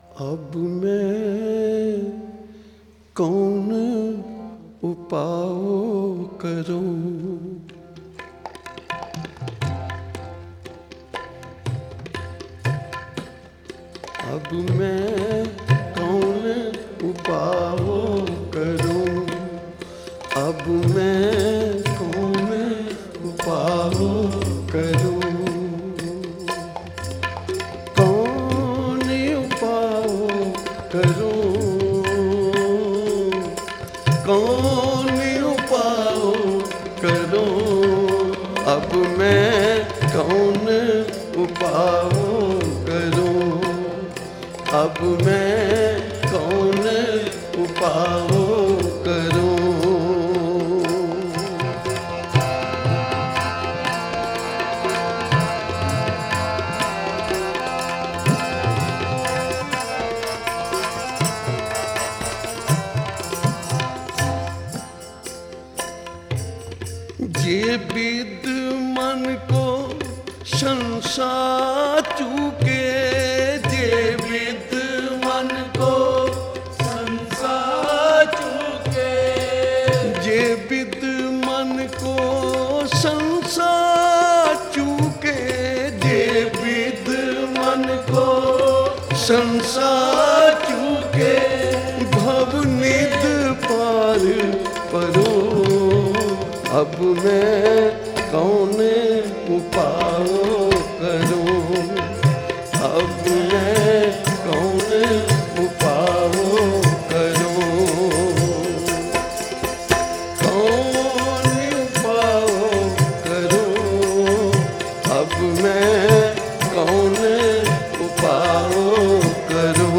Genre: Shabad Gurbani Kirtan Album Info